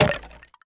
poly_explosion_bone.wav